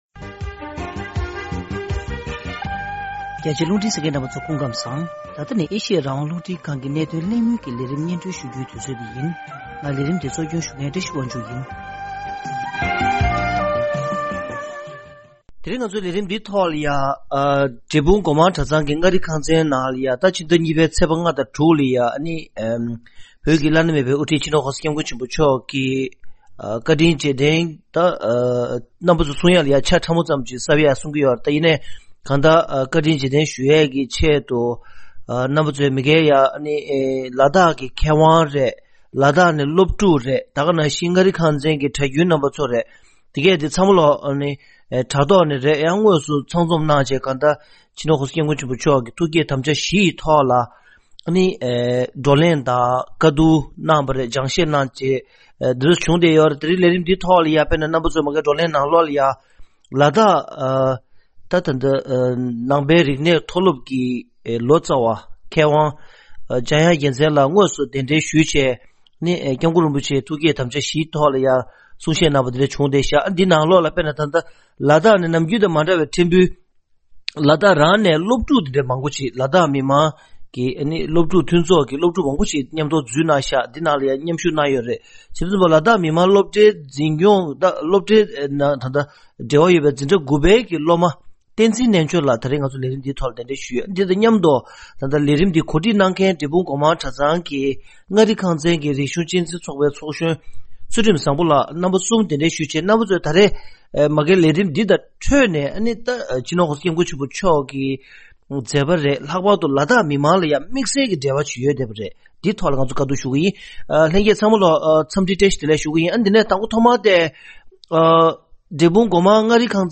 གདན་ས་ཆེན་པོ་འབྲས་སྤུངས་བཀྲ་ཤིས་སྒོ་མང་གྲྭ་ཚང་གི་མངའ་རིས་ཁང་ཚན་གྱིས་གོ་སྒྲིག་ལྟར་བོད་མིའི་བླ་ན་མེད་པའི་དབུ་ཁྲིད་སྤྱི་ནོར་༧གོང་ས་༧སྐྱབས་མགོན་ཆེན་པོ་མཆོག་ལ་བཀའ་དྲིན་རྗེས་དྲན་གྱི་བགྲོ་གླེང་དང་མཚན་ཕུད་དམ་བཅའ་འཇོག་གནང་མཛད་པ།